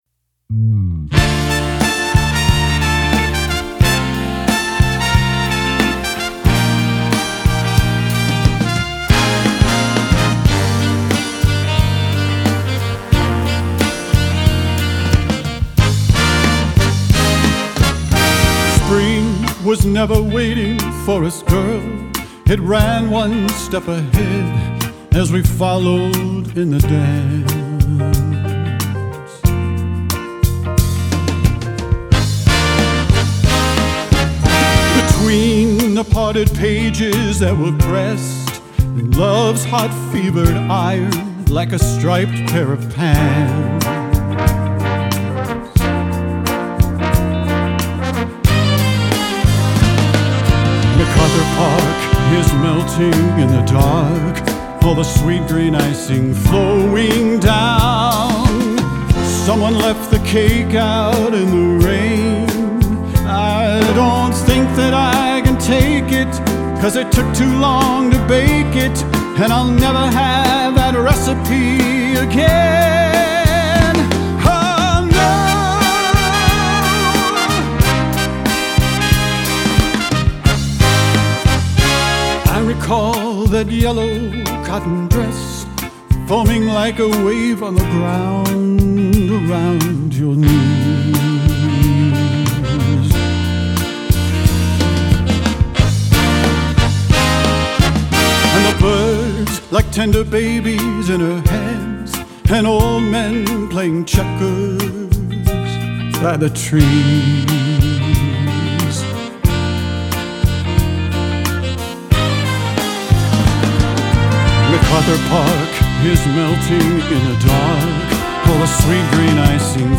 Big Band version
Solo for Alto Sax.